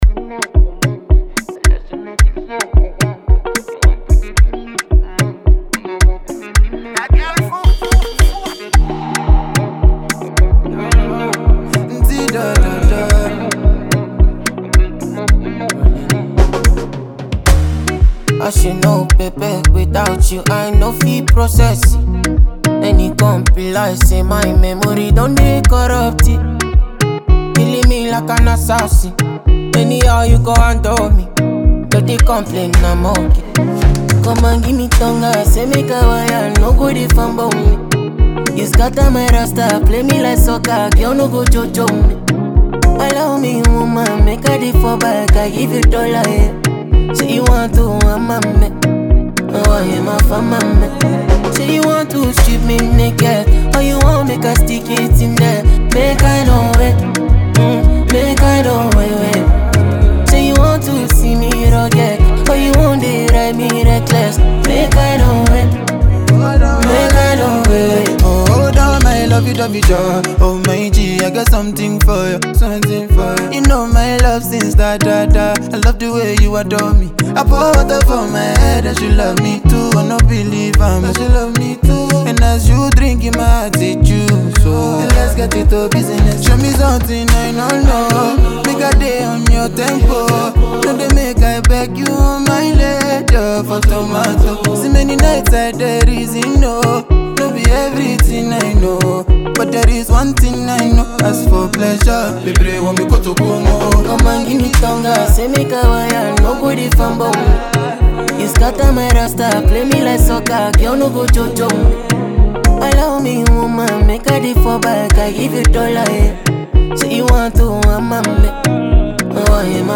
Enjoy this clean production from the duo.